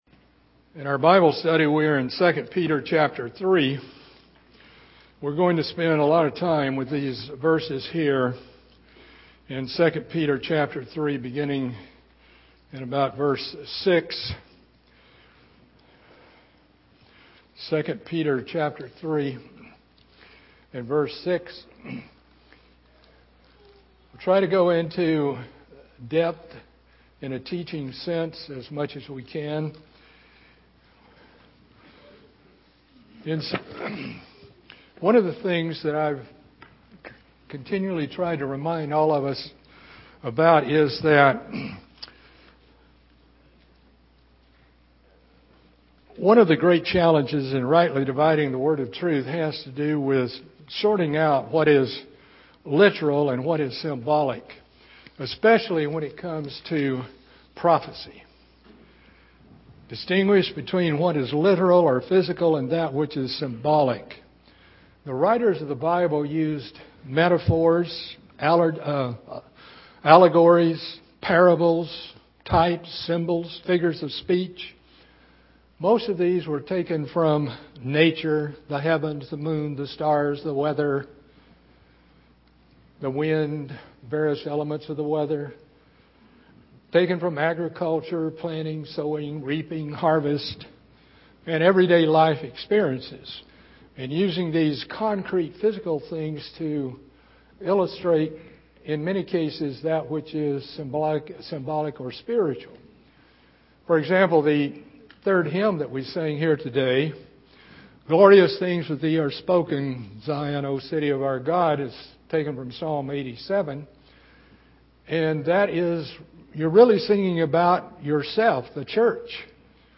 Continuing the Bible Study in the book of 2 Peter.